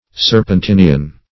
Serpentinian \Ser`pen*tin"i*an\, n.